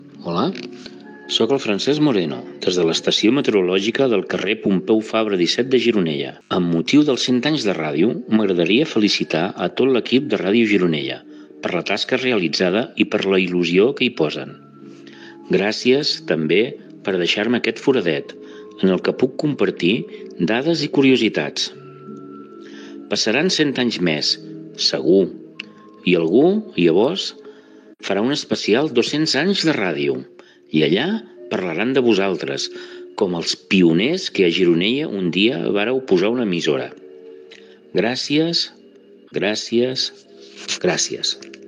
Pública municipal
Entreteniment
FM